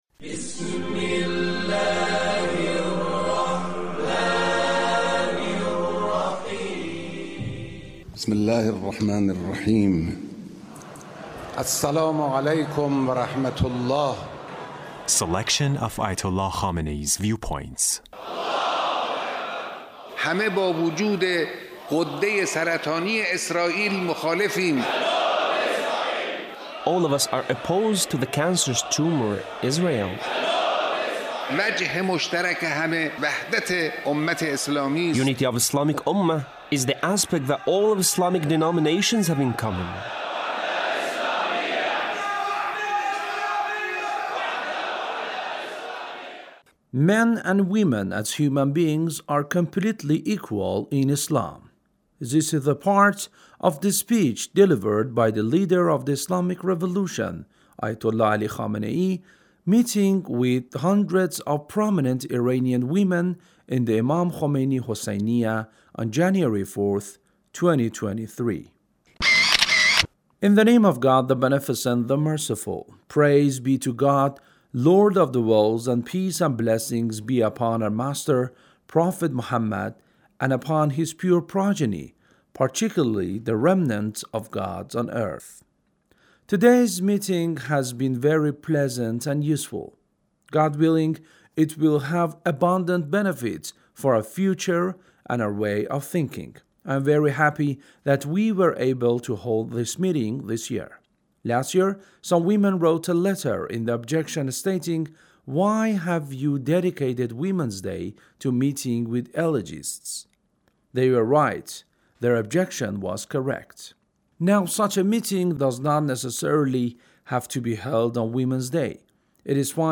Leader's Speech (1607)
Leader's Speech meeting with hundreds of prominent Iranian women